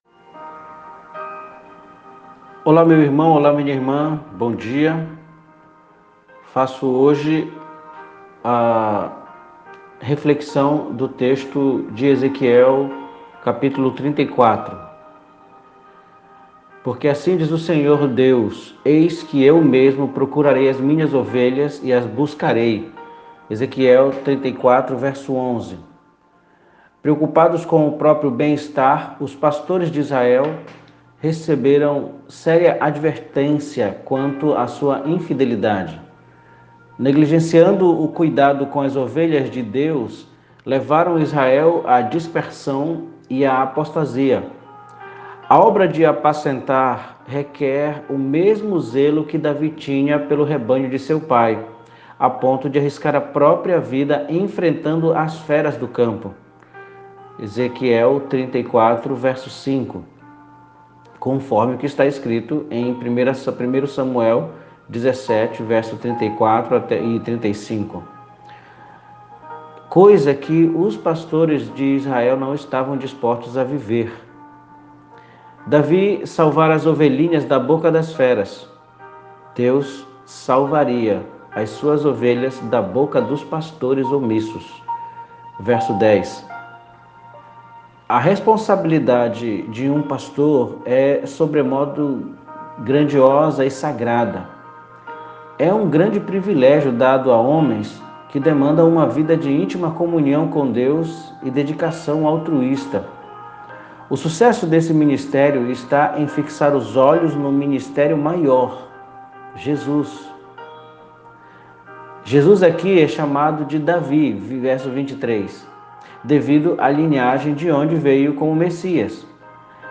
MEDITAÇÃO BÍBLICA